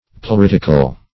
Search Result for " pleuritical" : The Collaborative International Dictionary of English v.0.48: Pleuritic \Pleu*rit"ic\, Pleuritical \Pleu*rit"ic*al\, a. [L. pleuriticus, Gr.